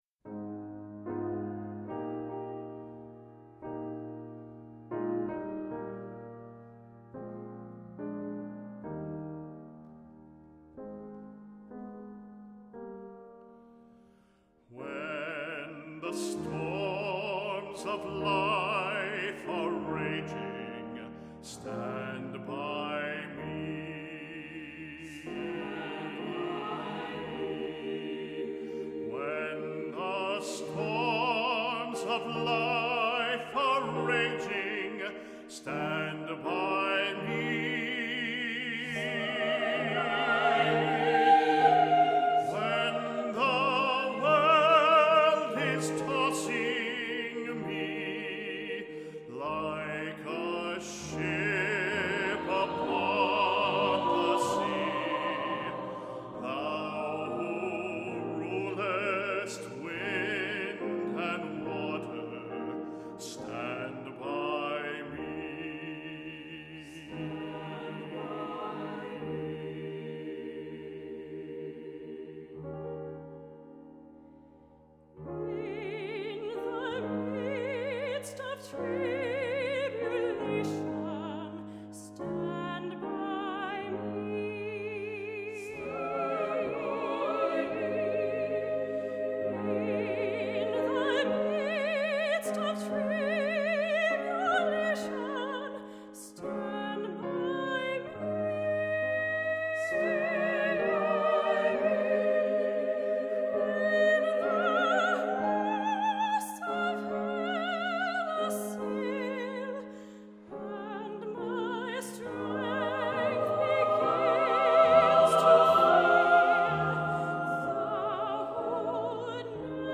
Accompaniment:      With Piano, Soprano Solo
Music Category:      Christian